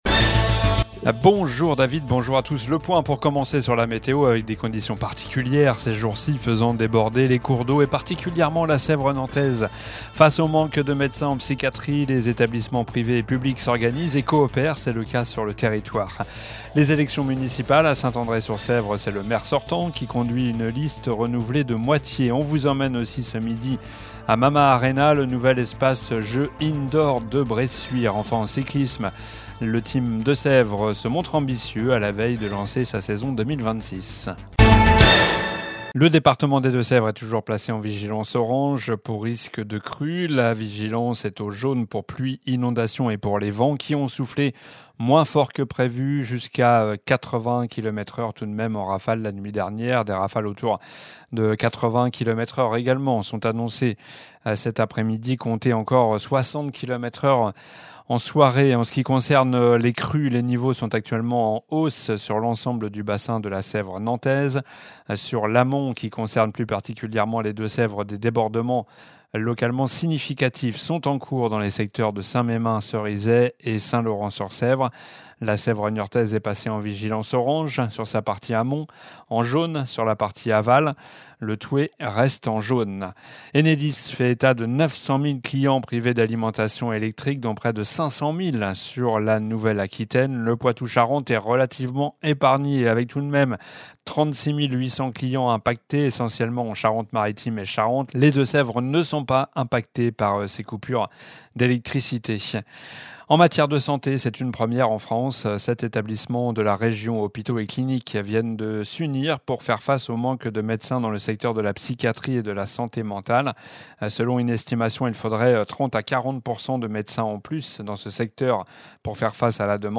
Journal du jeudi 12 février (midi)